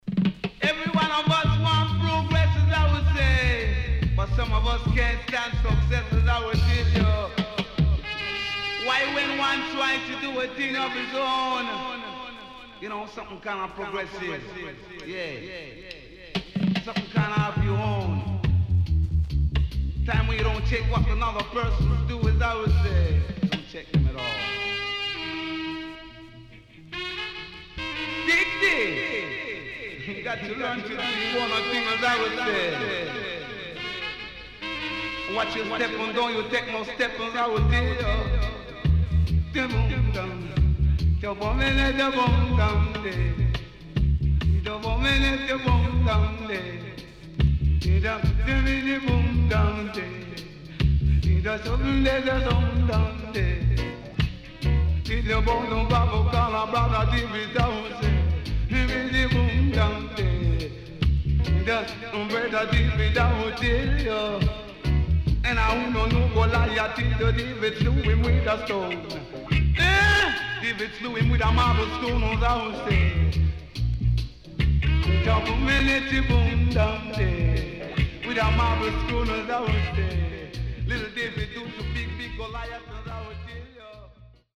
SIDE A:序盤少しノイズ入りますが良好です。